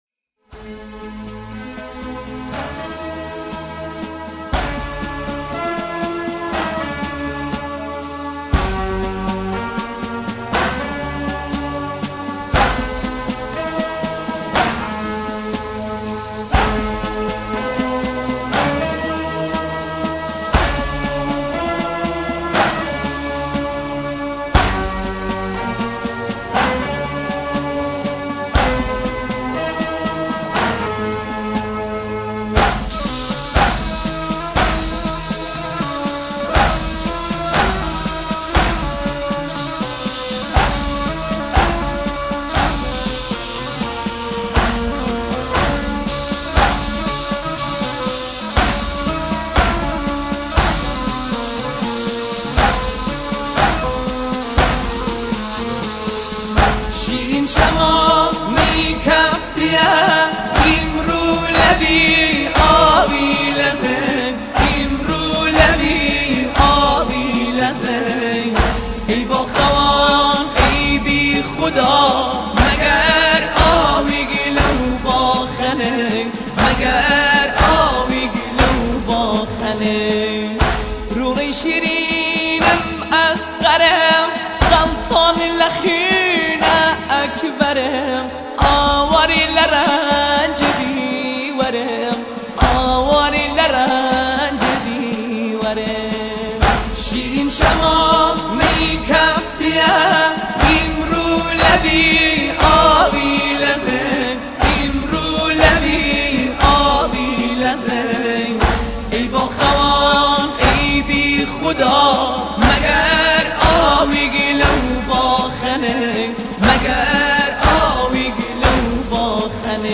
محرم